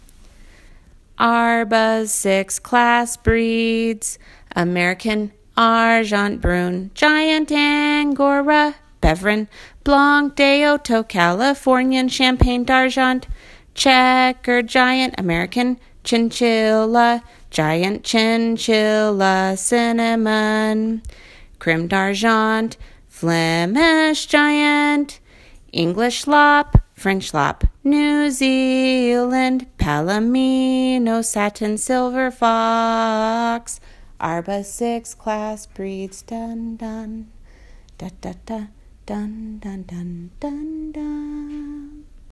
Then, I took yet another step down the rabbit hole of super-kooky and… created songs and chants.